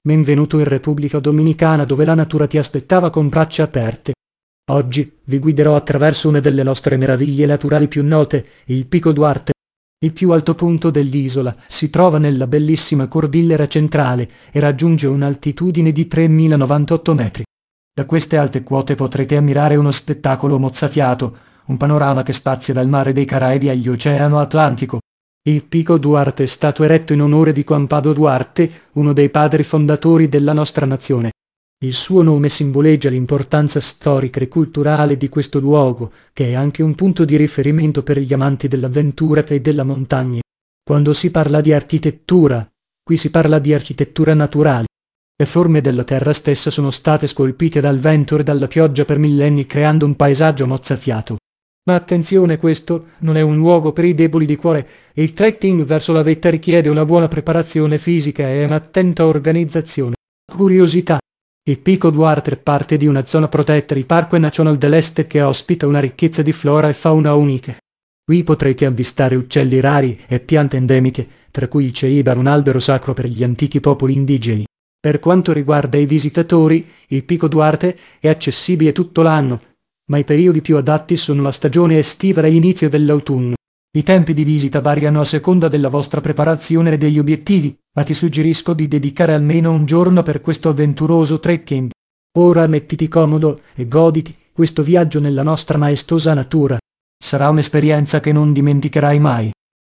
karibeo_api / tts / cache / 45b94b709bbdd595beafa7e199a97e3e.wav